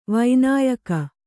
♪ vaināyaka